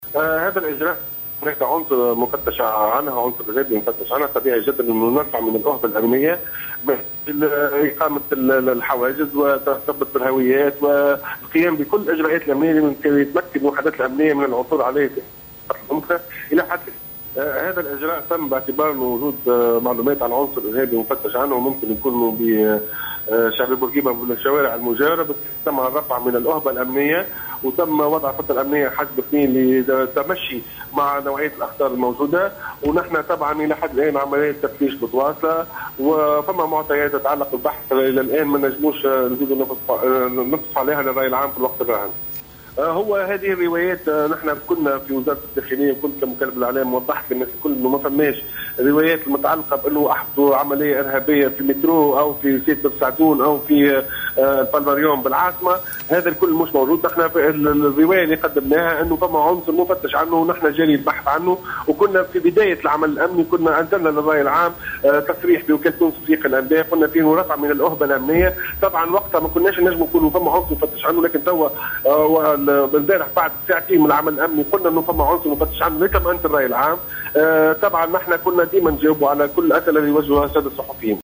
تصريح هاتفي للقناة الوطنية